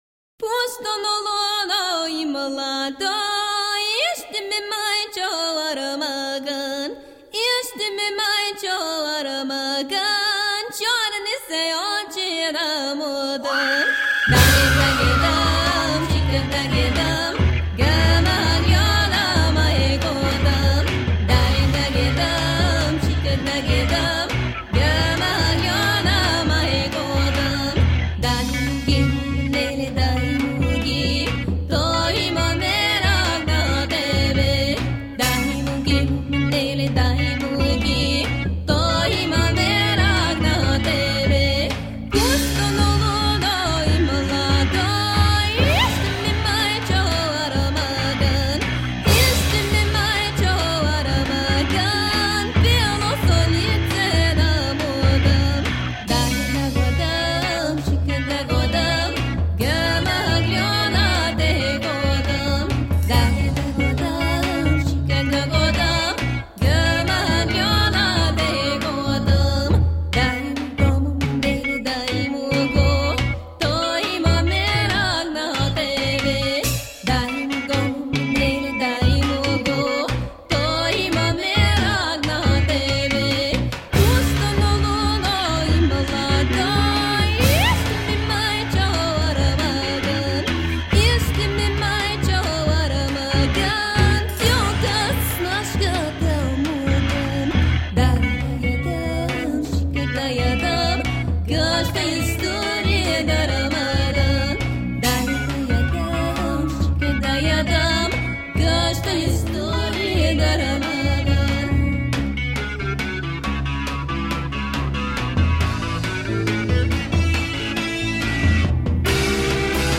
Psychedelic power meets hypnotic bulgarian rhythms.
Tagged as: World, Folk, Folk